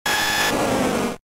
Cri de Florizarre K.O. dans Pokémon Diamant et Perle.